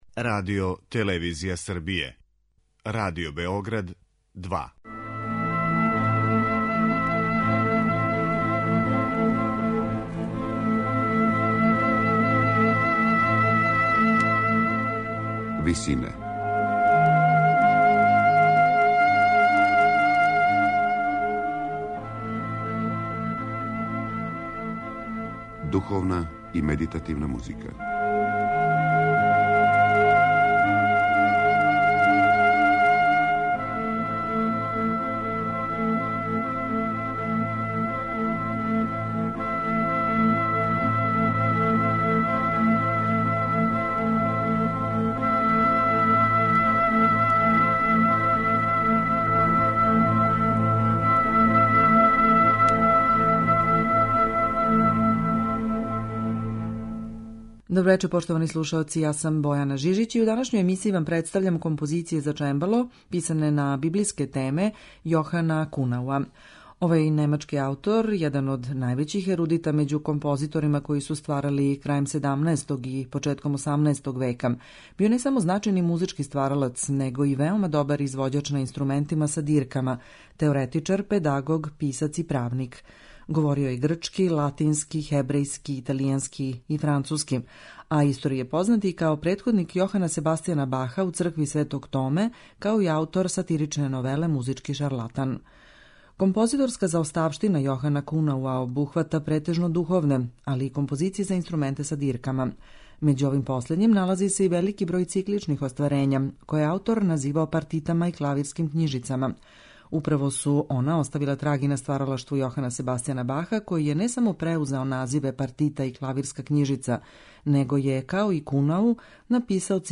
у извођењу чембалисткиње
медитативне и духовне композиције